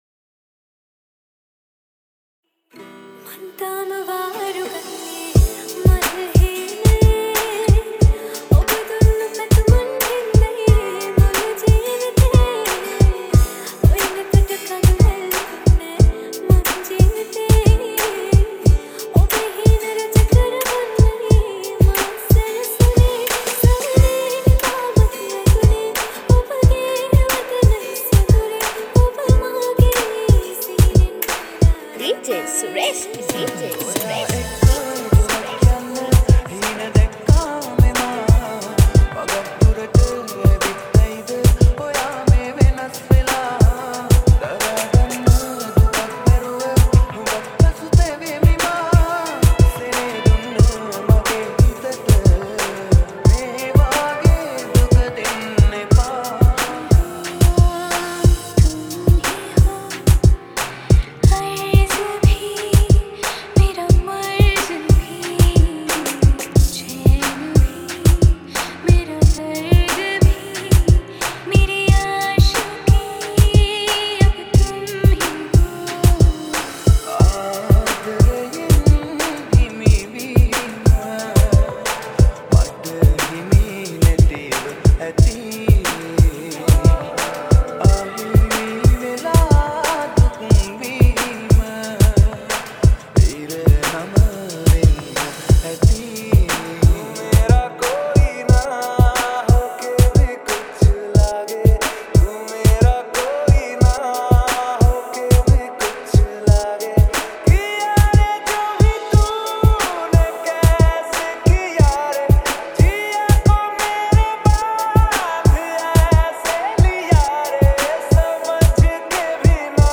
DJ Remix